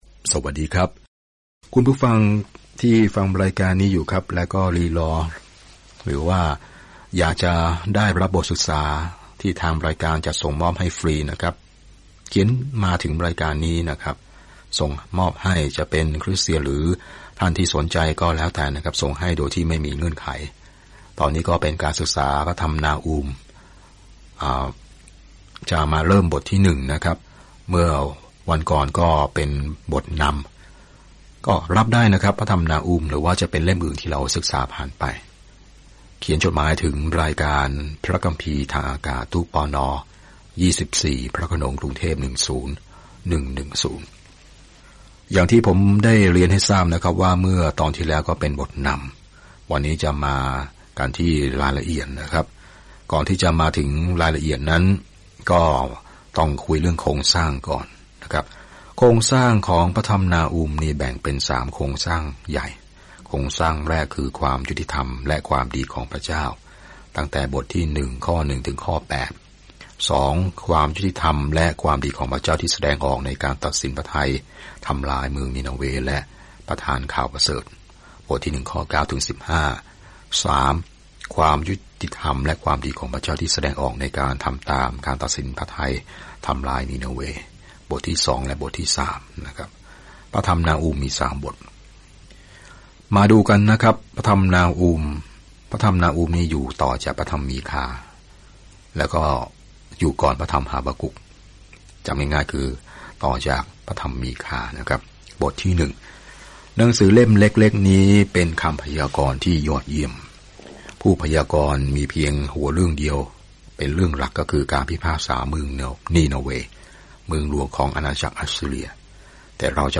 นาฮูม ซึ่งชื่อหมายถึงการปลอบโยน นำข่าวสารแห่งการพิพากษามาสู่ศัตรูของพระเจ้า และนำทั้งความยุติธรรมและความหวังมาสู่อิสราเอล เดินทางทุกวันผ่านนาฮูมในขณะที่คุณฟังการศึกษาด้วยเสียงและอ่านข้อที่เลือกจากพระวจนะของพระเจ้า